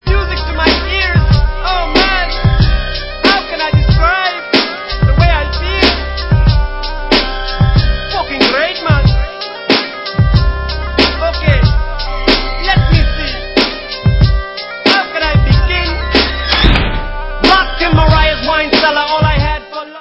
Dance/Hip Hop